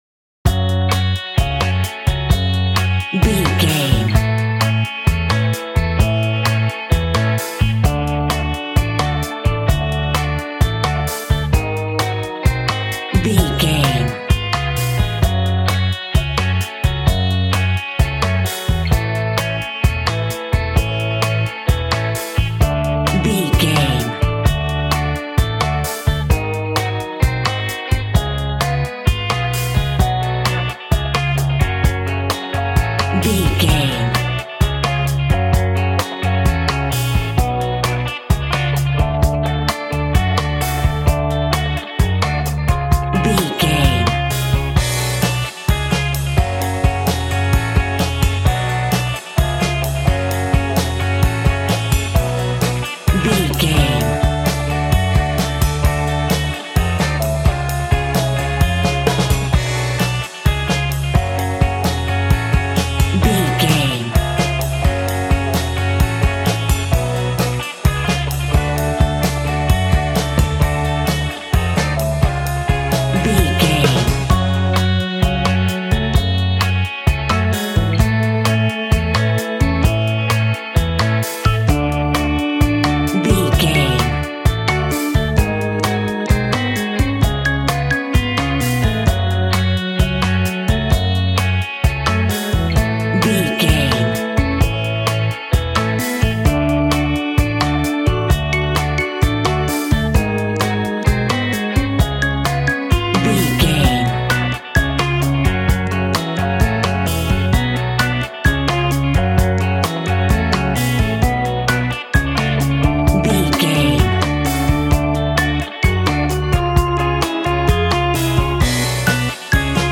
Aeolian/Minor
G♭
uplifting
bass guitar
electric guitar
drums
cheerful/happy